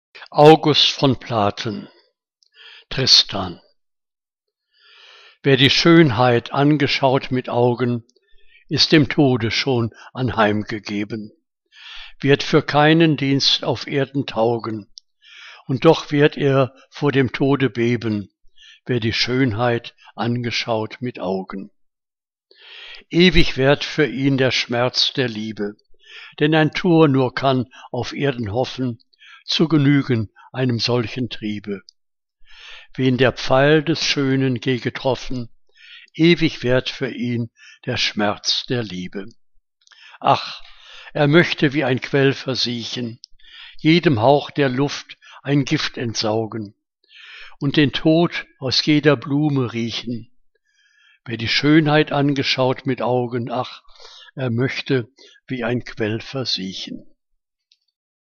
Liebeslyrik deutscher Dichter und Dichterinnen - gesprochen (August von Platen)